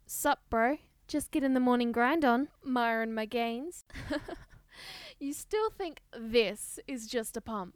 A very talented young lady took it upon herself to record (almost) every single line of dialogue Sarah speaks in the story.